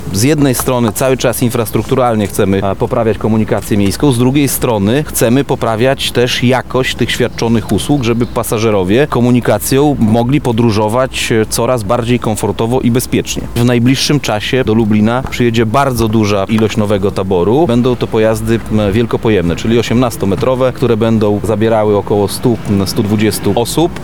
Tomasz Fulara – mówi Tomasz Fulara, Zastępca Prezydent Miasta Lublin ds. Inwestycji i Rozwoju.